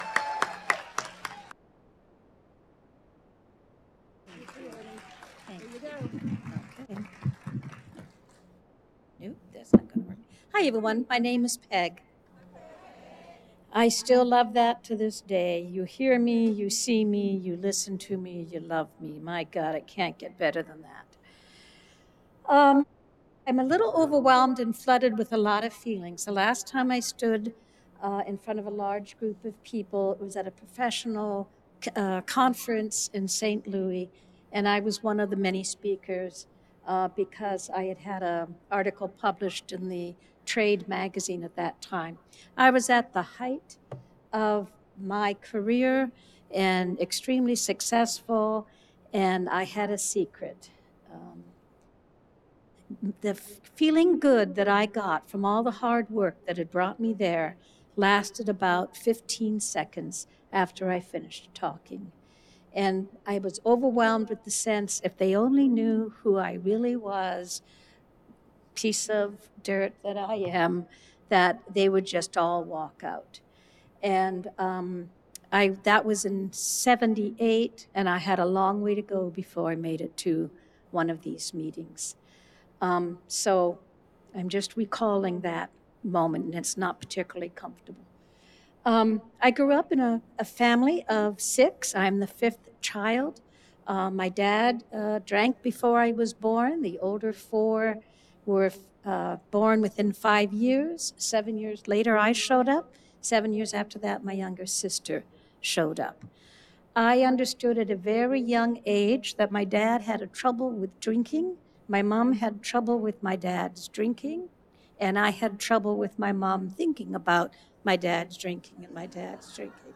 46th So Cal Al-Anon Convention